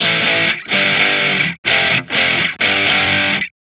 Guitar Licks 130BPM (1).wav